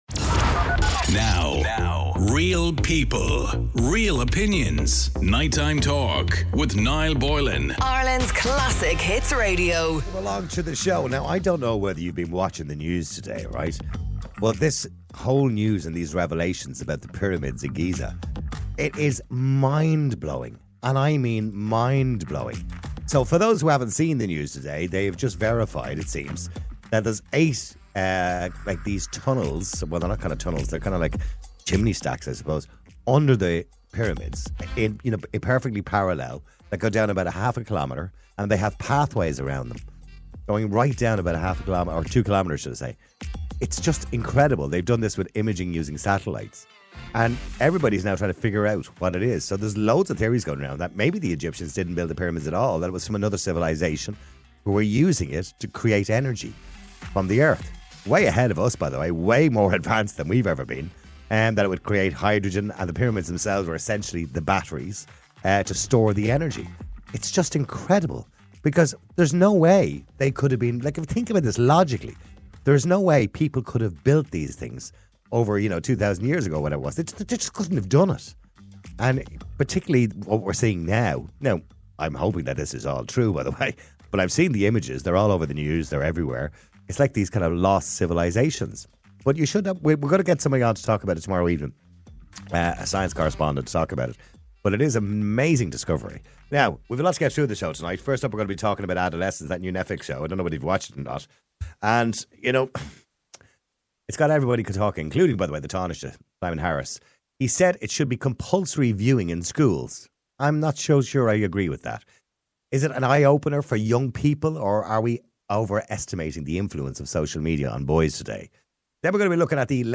A radio talk show that cares about YOU 9PM - MIDNIGHT MONDAY TO THURSDAY ON IRELAND'S CLASSIC HITS RADIO